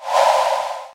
swipe.ogg